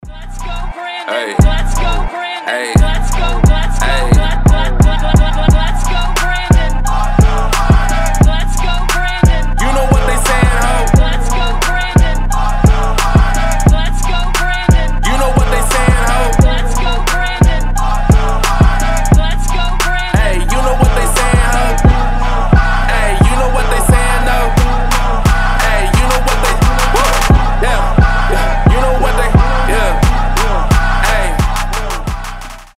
Хип-хоп
басы
качающие
Рэп-песня о недовольстве Джо Байденом